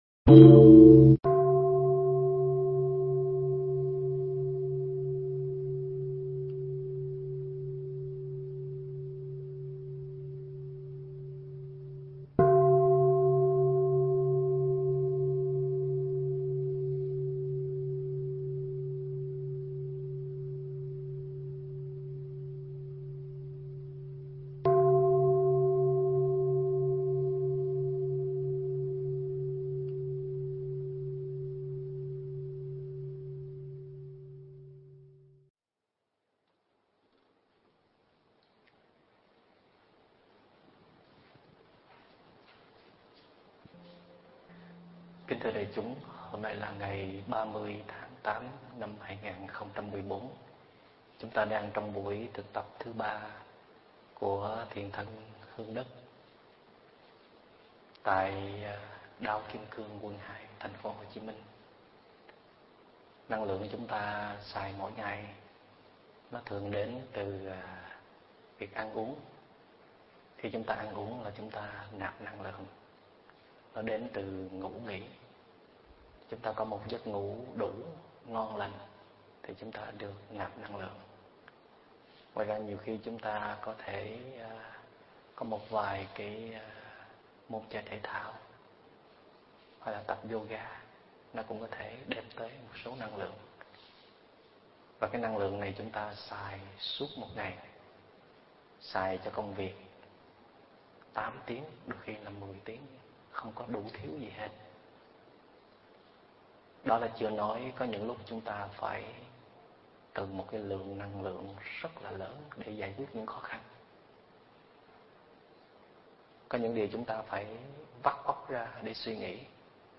MP3 Thuyết pháp Khơi Dậy Sức Mạnh Bên Trong